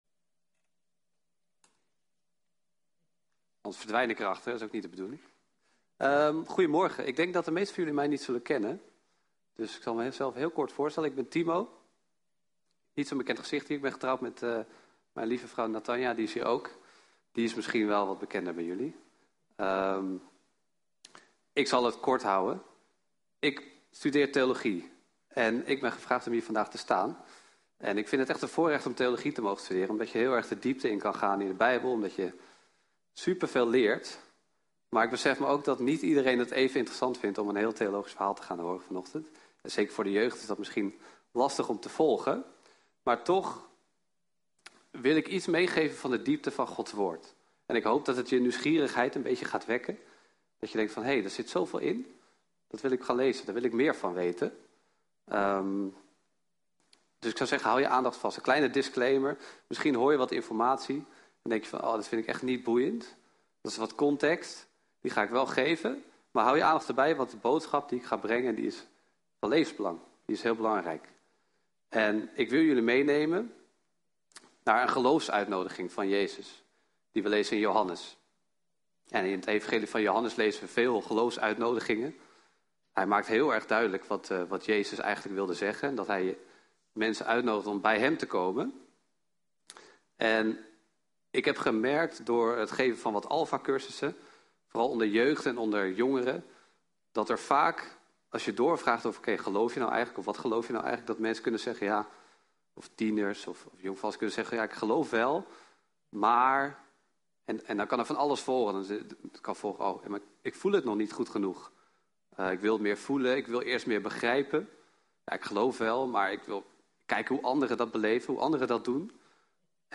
29 juni Jeugddienst - Water!